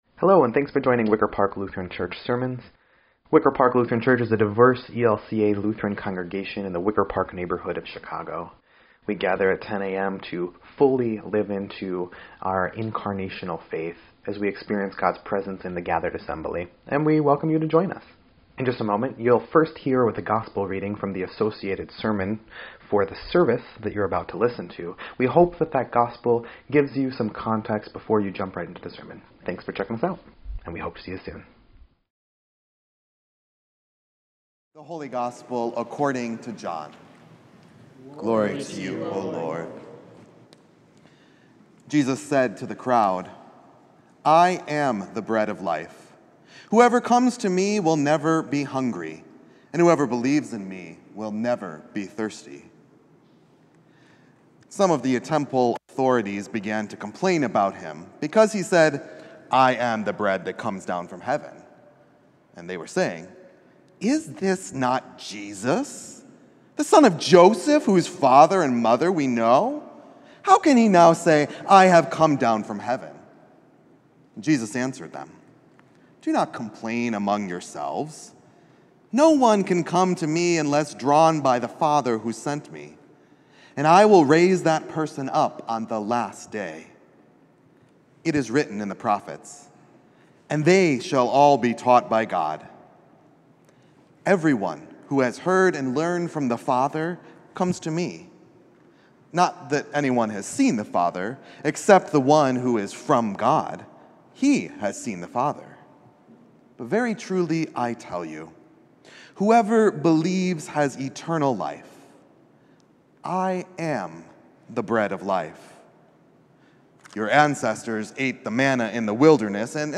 8.11.24-Sermon_EDIT.mp3